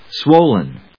音節swol・len 発音記号・読み方
/swóʊlən(米国英語), swˈəʊlən(英国英語)/